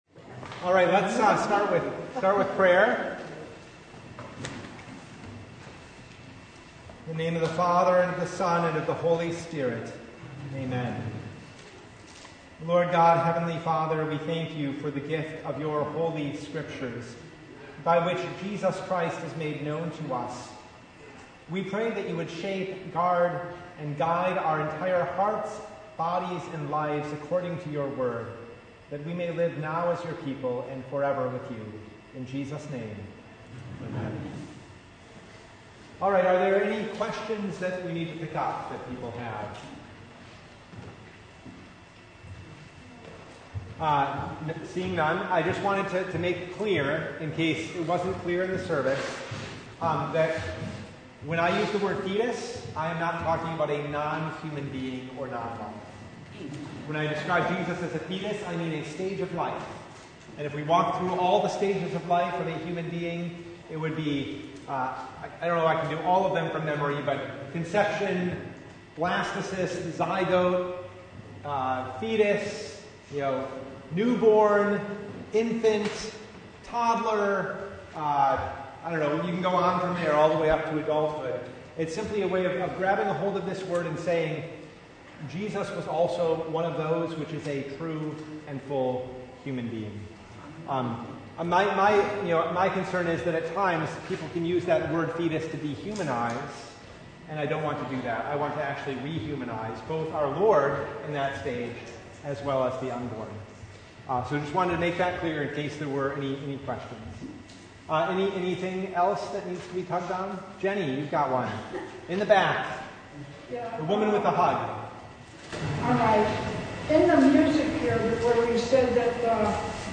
Bible Study « The Nativity of our Lord